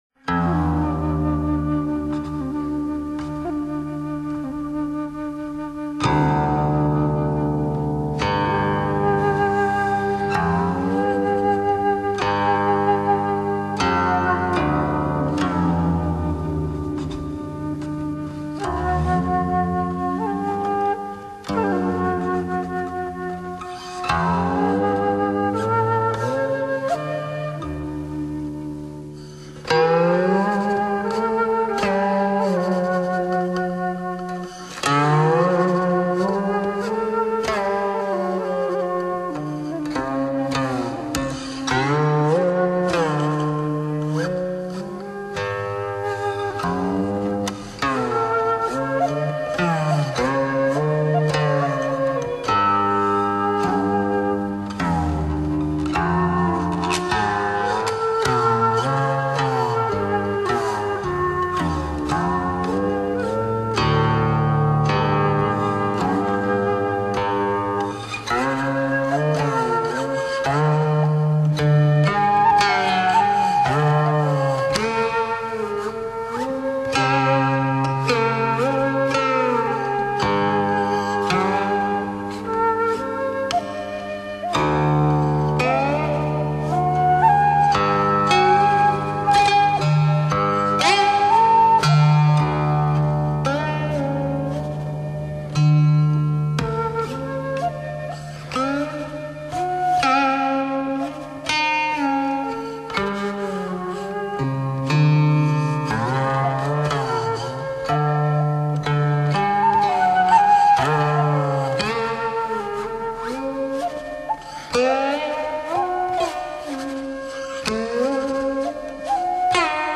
琴筝箫协奏